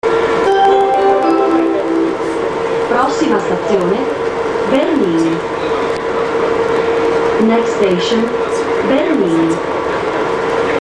Departure Procedure and Sounds
Buzzing when doors close (similar to Paris).